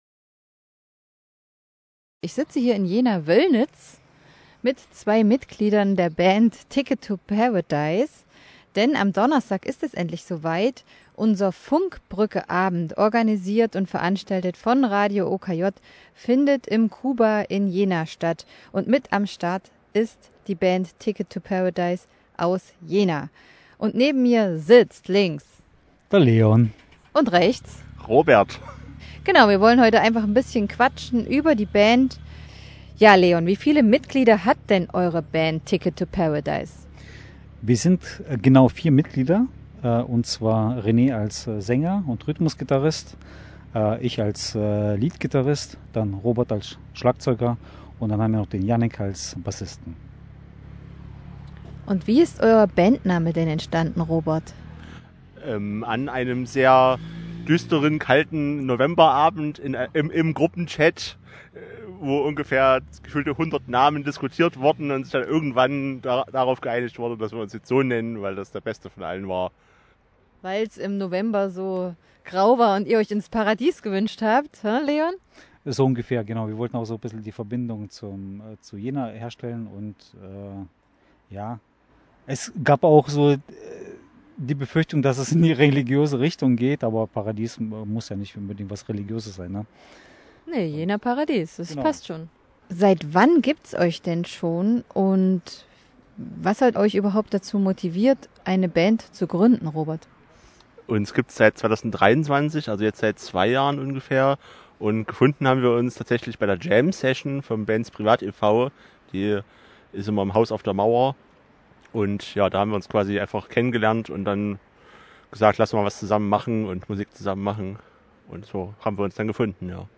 T2P_Interview_Funkbruecke.mp3